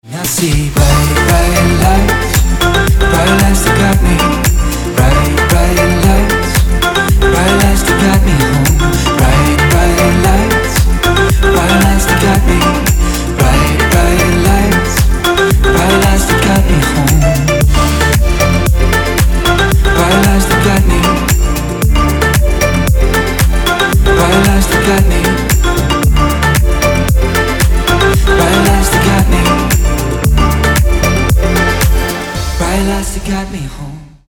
• Качество: 224, Stereo
мужской вокал
dance
Electronic
EDM
club